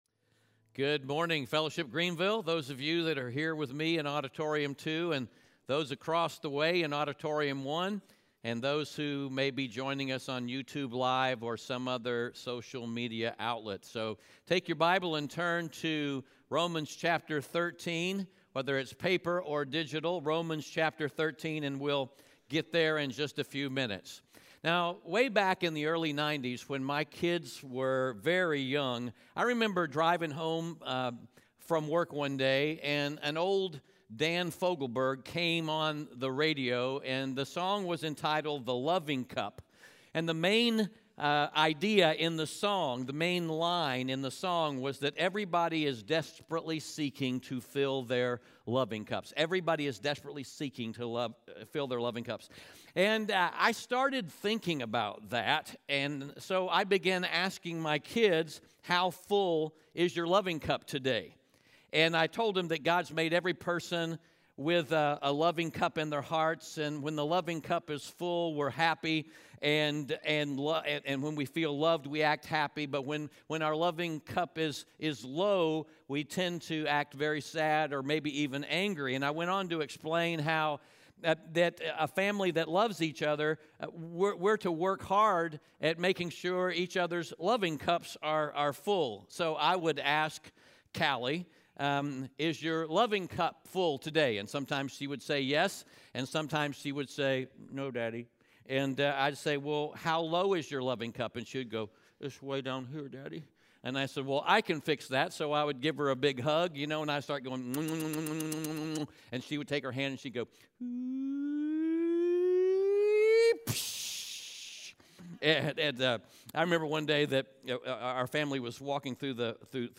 Romans 13:8-14 Audio Sermon Notes (PDF) Ask a Question God has made us to live in loving relationships with each other.